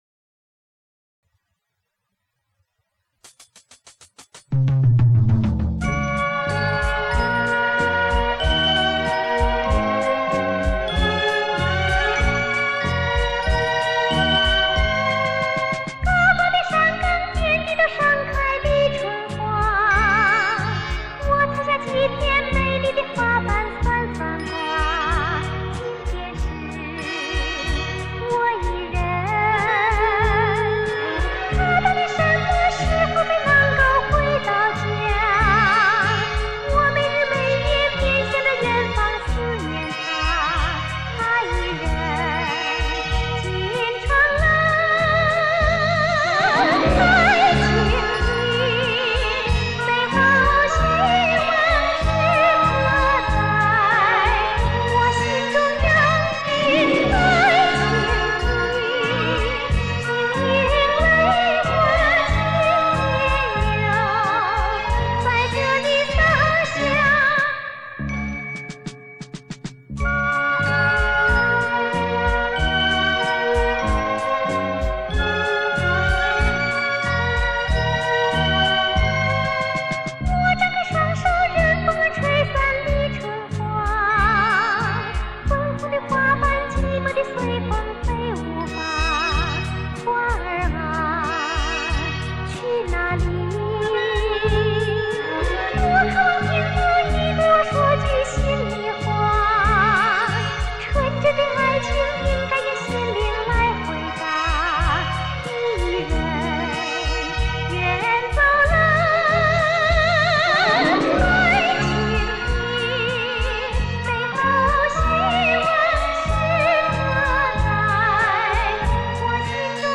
日本歌曲